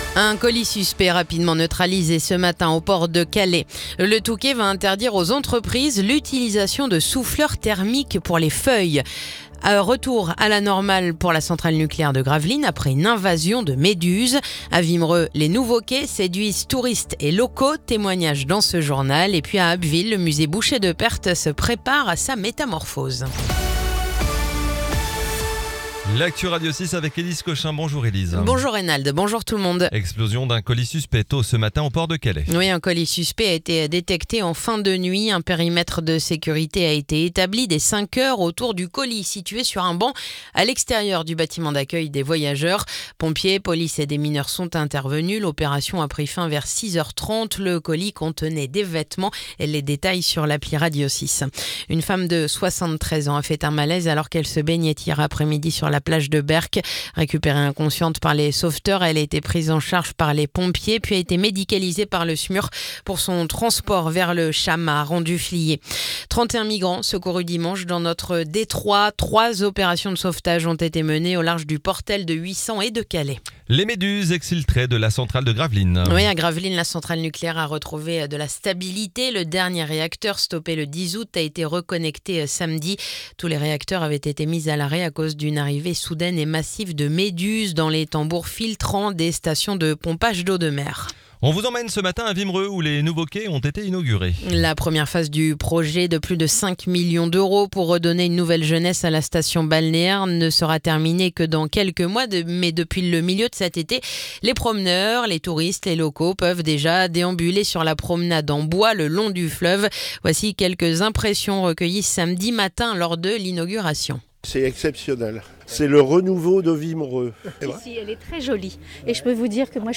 Le journal du mardi 26 août